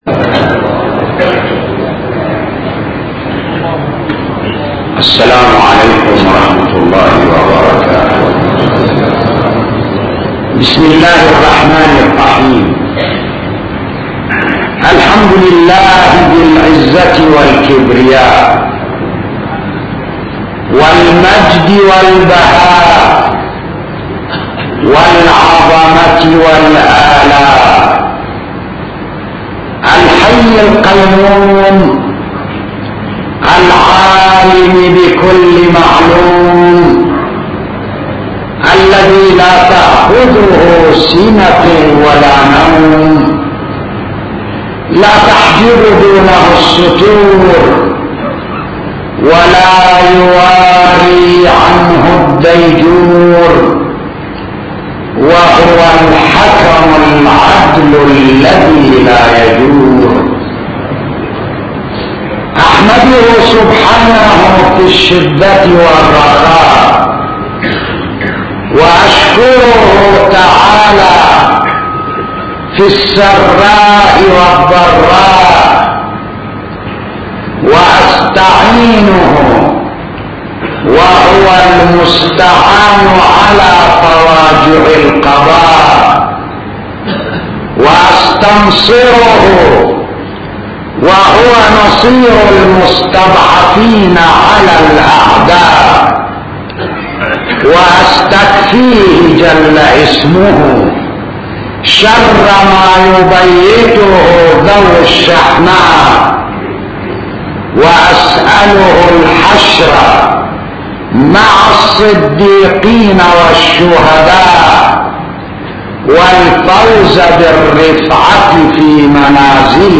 خطب